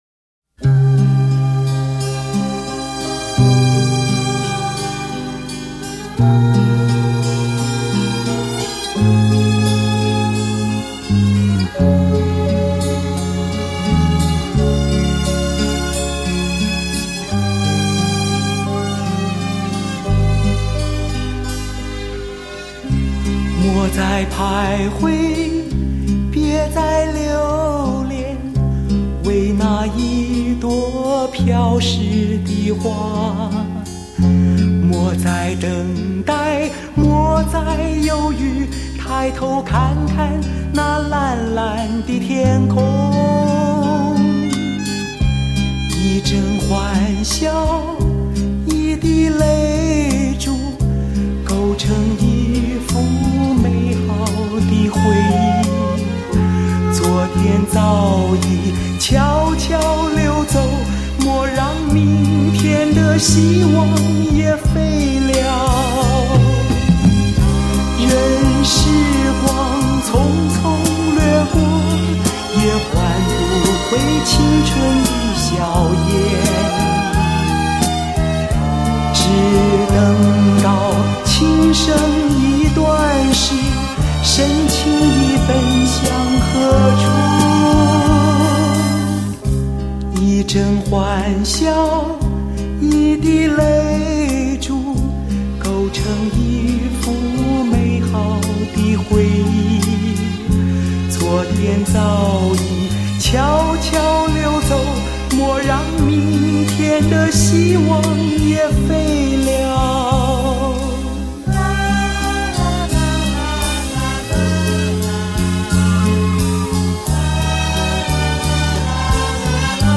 格调清新洒脱 让人耳目一新
全部24Bit数码录音